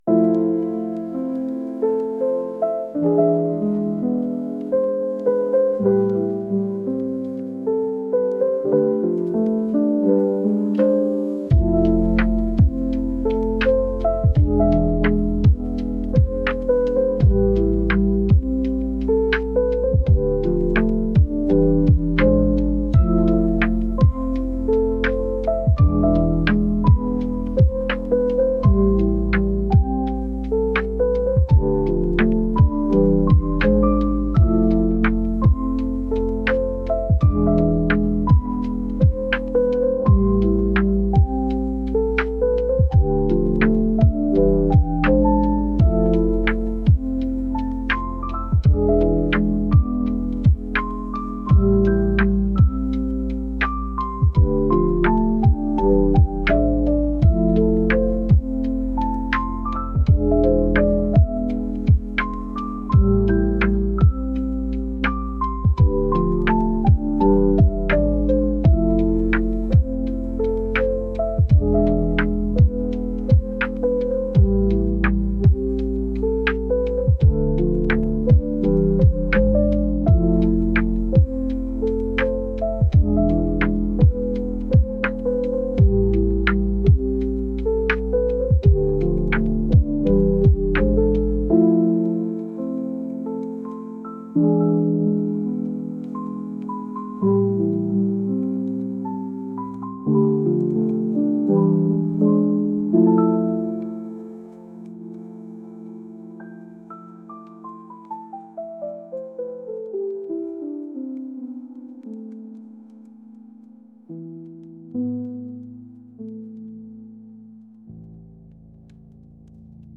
ゆったりとしたピアノ曲です。